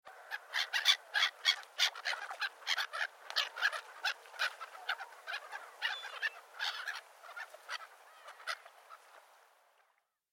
دانلود آهنگ دریا 10 از افکت صوتی طبیعت و محیط
دانلود صدای دریا 10 از ساعد نیوز با لینک مستقیم و کیفیت بالا
جلوه های صوتی